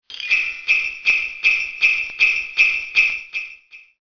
grelots.mp3